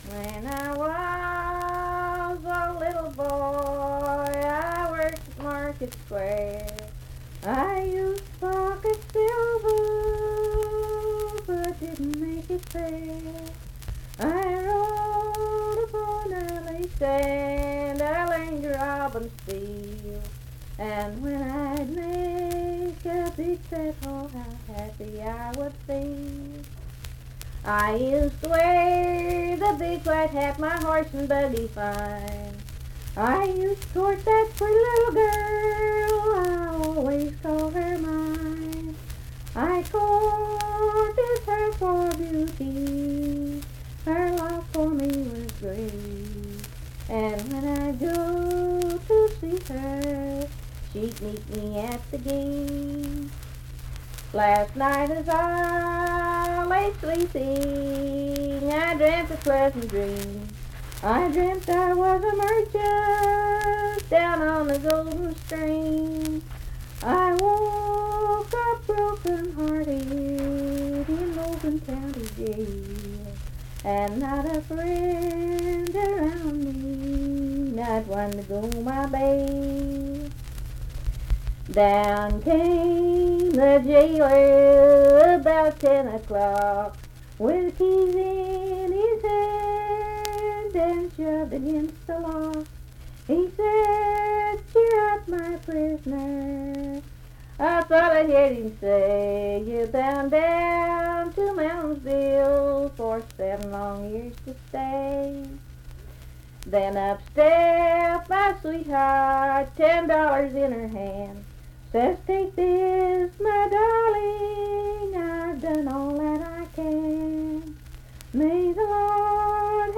Unaccompanied vocal music
Verse-refrain 6(8).
Voice (sung)